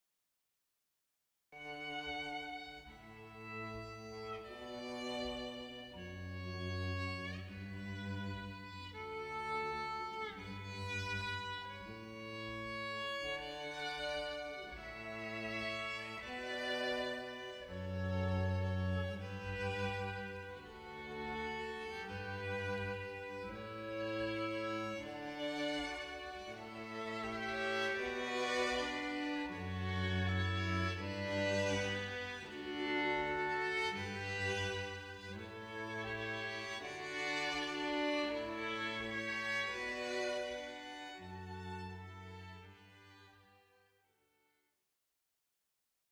Klassiska stråkkvartetter
En stråkkvartett från Göteborg